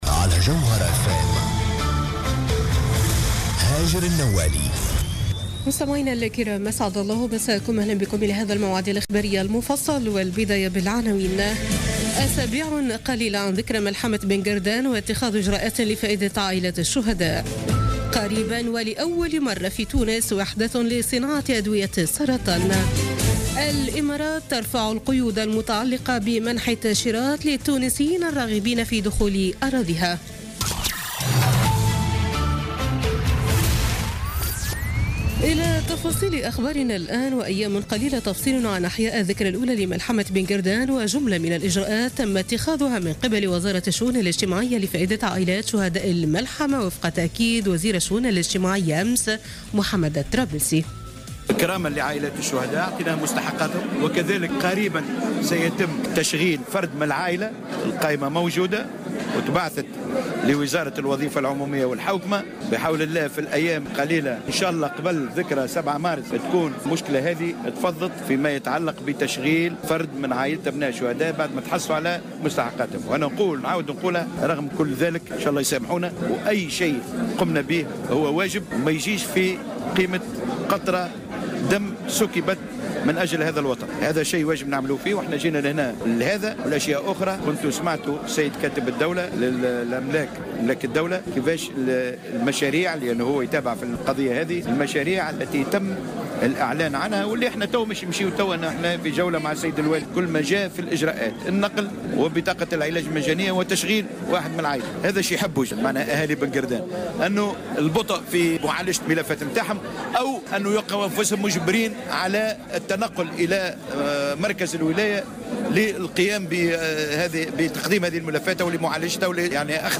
نشرة أخبار منتصف الليل ليوم الأحد 12 فيفري 2017